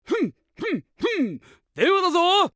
着信音
W-03 ダン：(CV：山口勝平) 「ふんっふんっふんっ、電話だぞー」